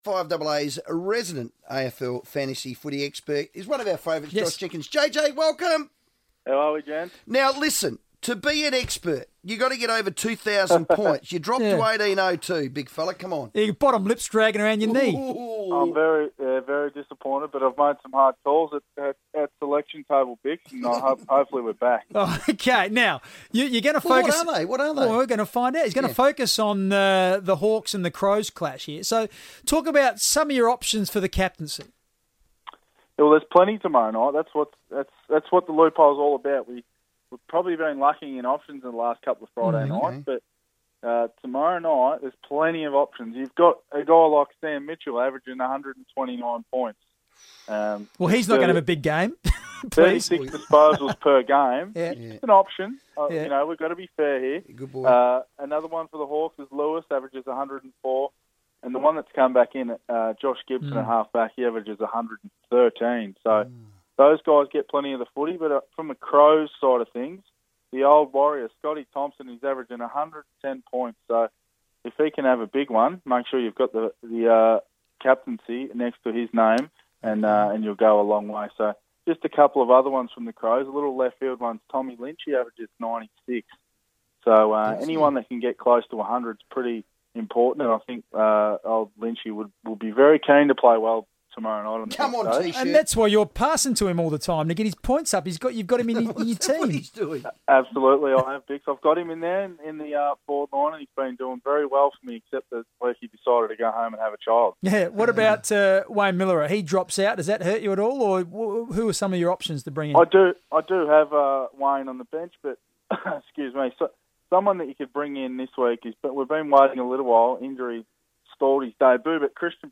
Josh Jenkins shares his AFL Fantasy insights on FIVEaa radio